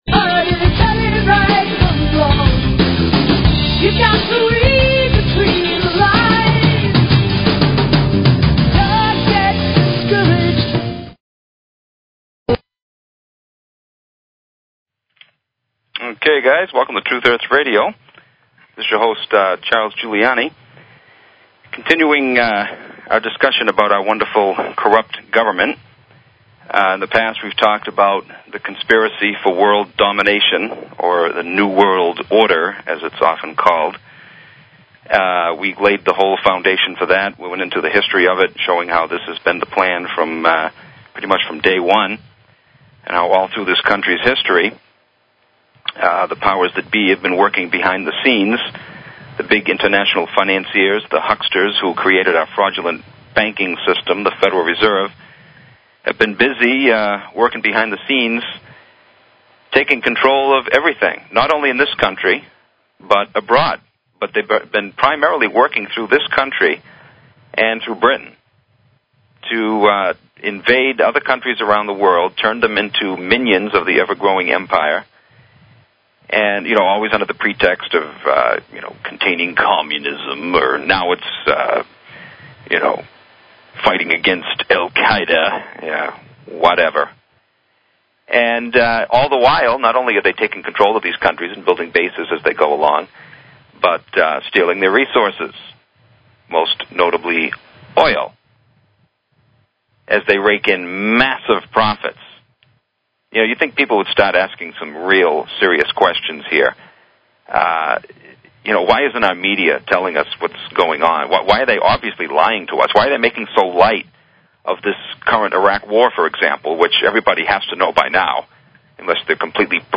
Talk Show Episode, Audio Podcast, Truth_Hertz_Radio and Courtesy of BBS Radio on , show guests , about , categorized as